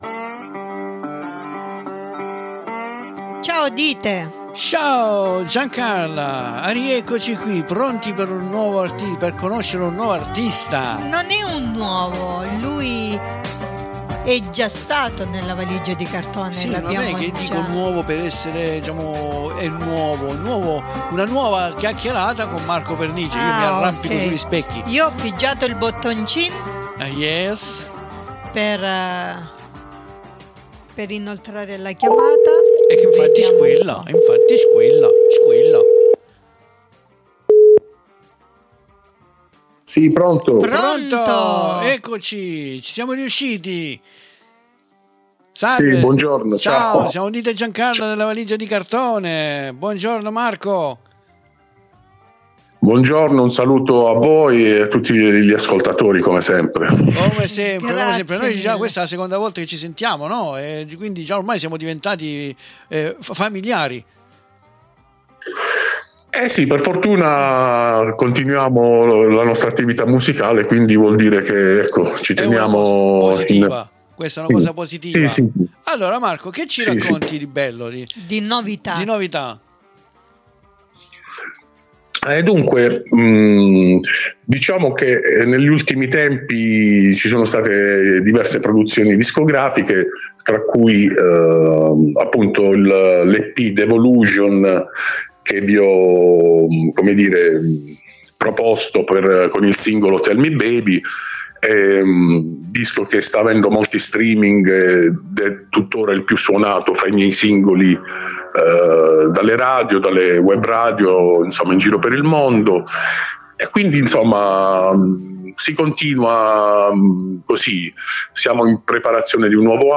IL RESTO VI CONSIGLIO DI ASCOLTARLO DALL'INTERVISTA CONDIVISA QUI IN DESCRIZIONE